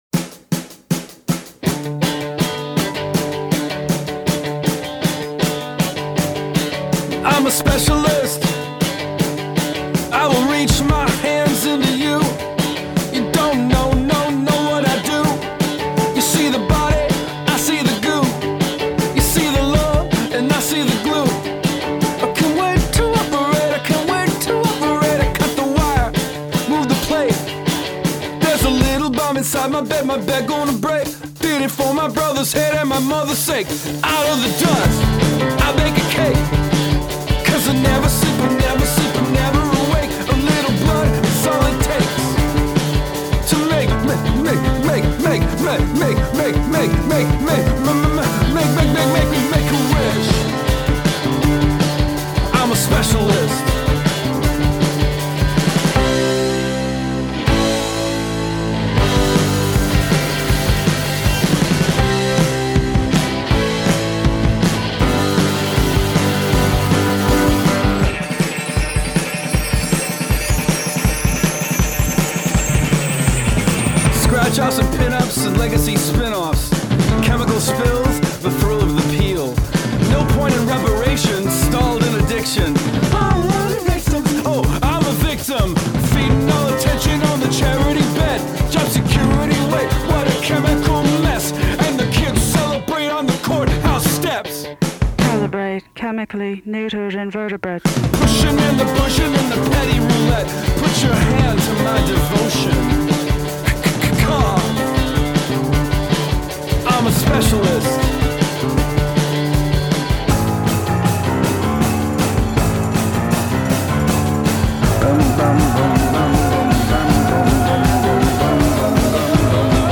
a punk-inflected take on '80s college rock melody
a remarkably direct and frenetic cut